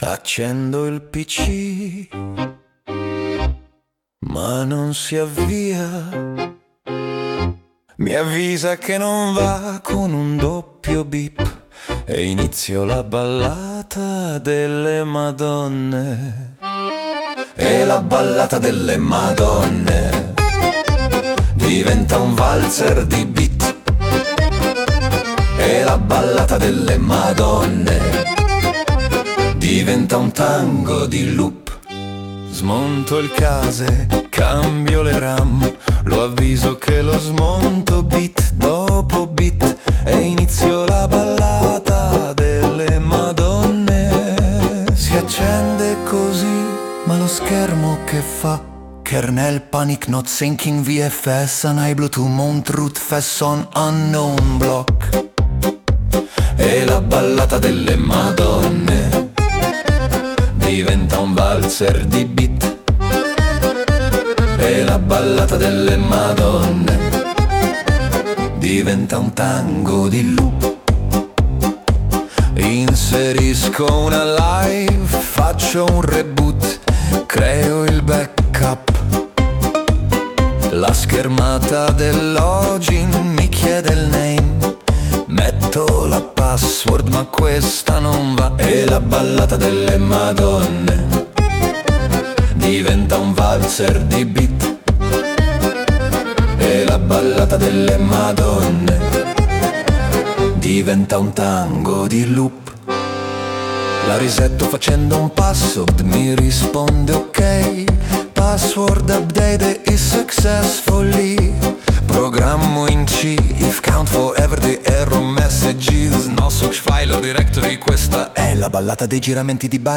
La ballata del coder disperato (Male Vocal, Tech Tango, Valzer Elettronico